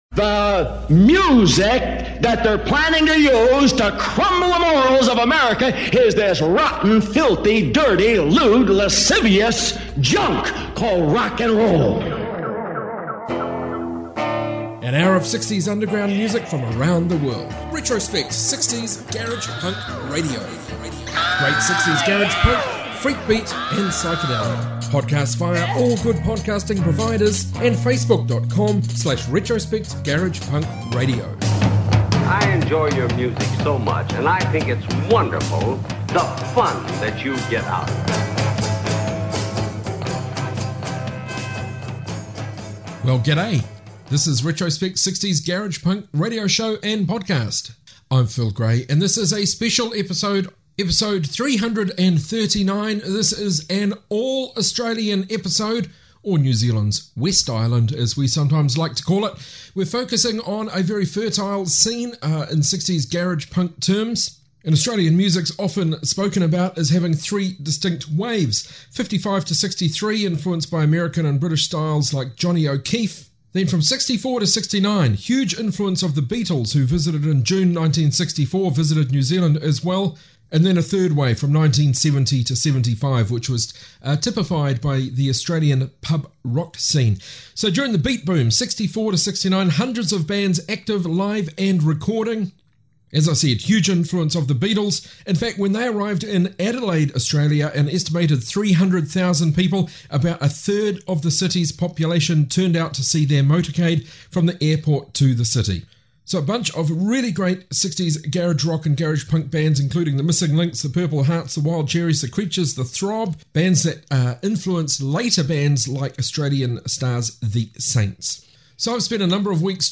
Retrospect 60s Garage Punk Show episode 39